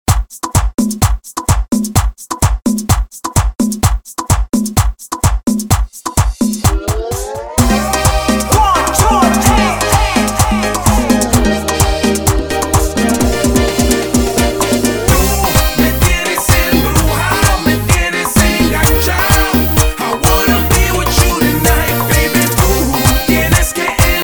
DJ and producer of tech house & house music
His style is unique and electrifying.
DJ